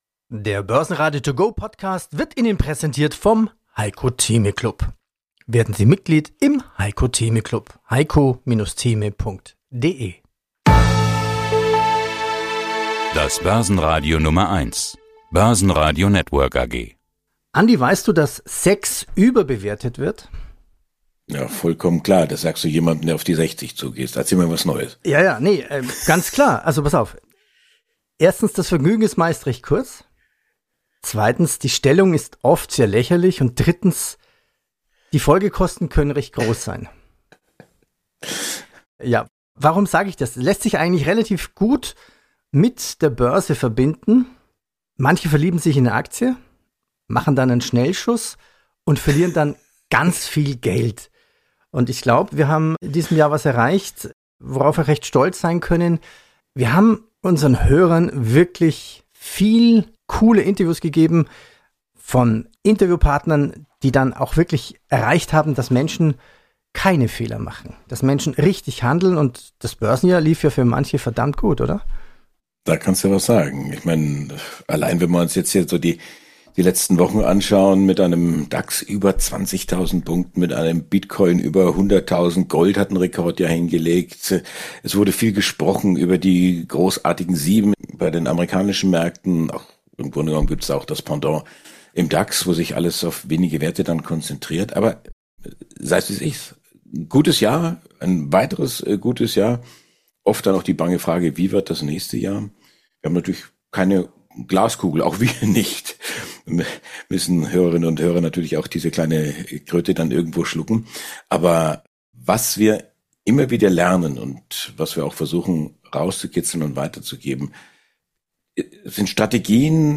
Wir erzählen euch, liebe Hörerinnen und Hörer, wie wir arbeiten, wie wir mit den Vorständen, Analysten, Wissenschaftlern und Kollegen sprechen, uns austauschen, diskutieren. Ein akustischer Blick hinter die Kulissen des Börsenradio. Über allem die Frage: Was war dein bestes Interview 2024?